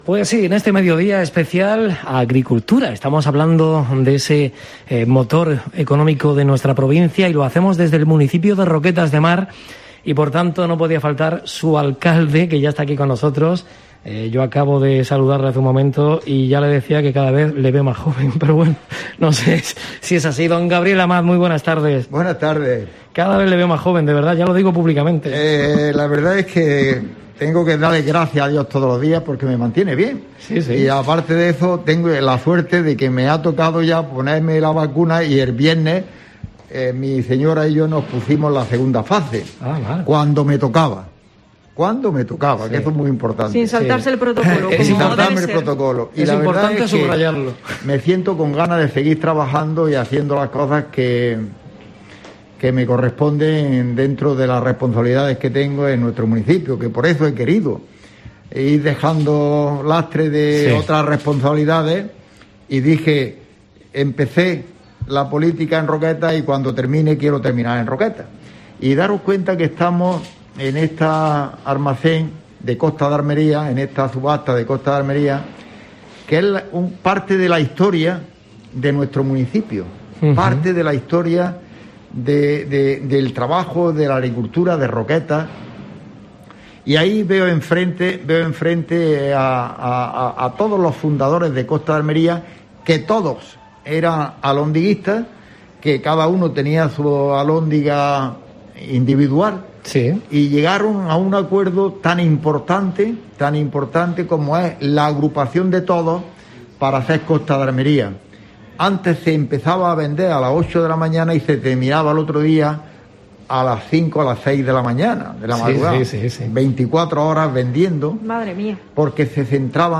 El alcalde roquetero interviene en la I Semana de la agricultura en Roquetas de Mar, organizada por COPE Almería.